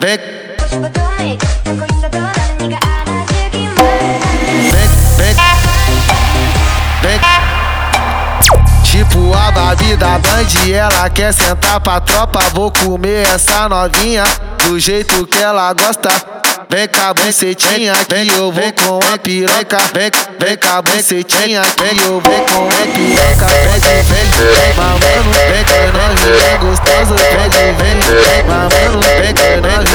Скачать припев, мелодию нарезки
Latin